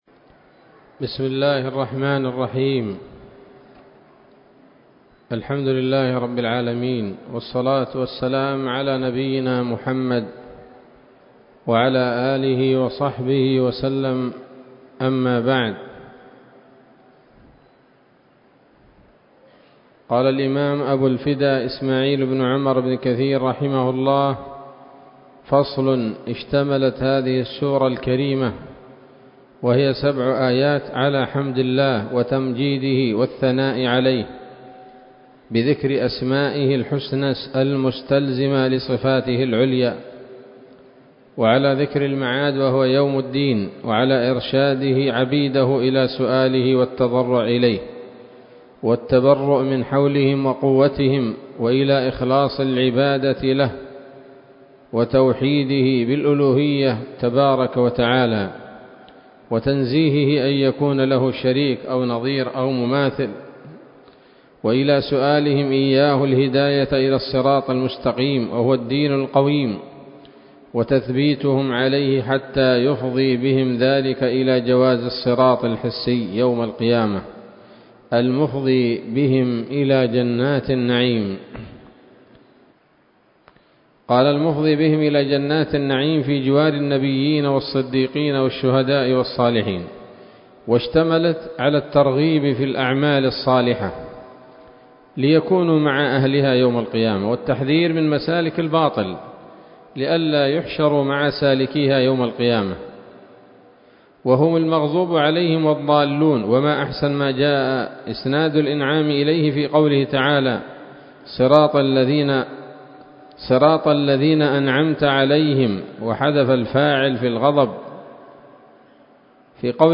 الدرس الحادي والعشرون من سورة الفاتحة من تفسير ابن كثير رحمه الله تعالى